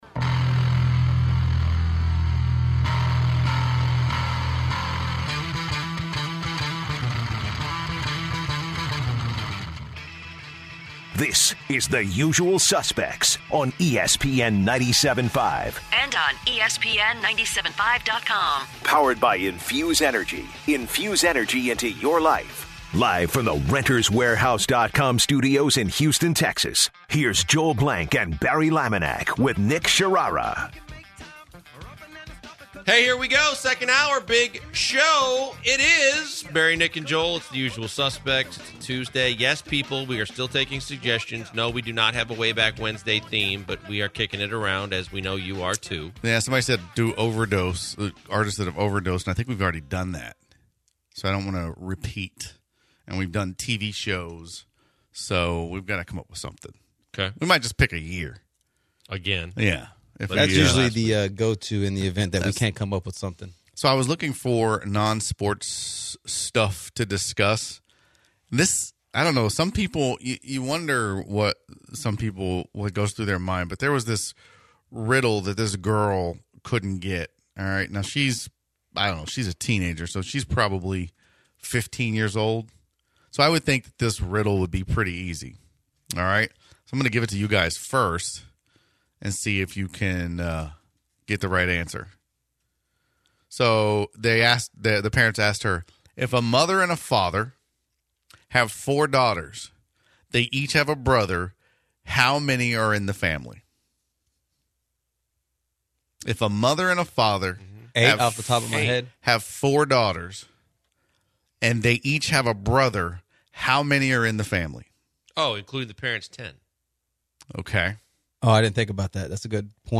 In football news, the NFL has fixed the catch rule and they close the hour taking some calls.